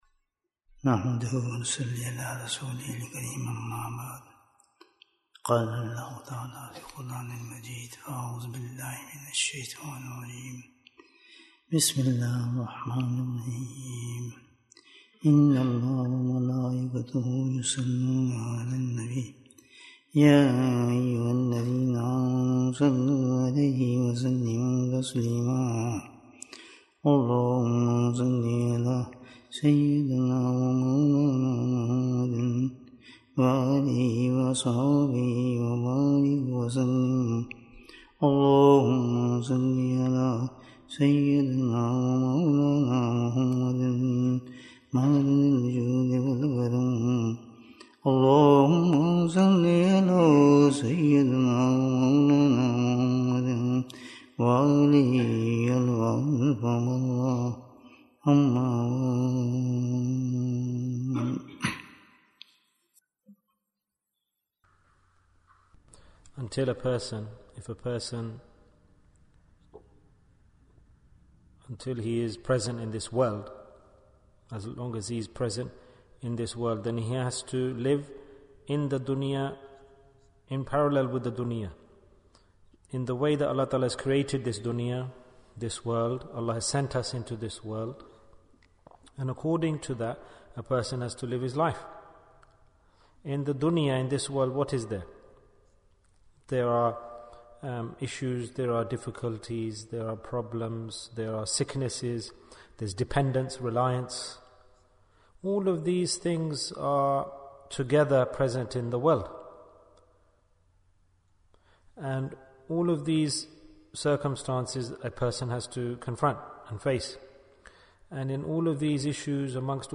Barakah of Sadaqah Bayan, 59 minutes2nd March, 2023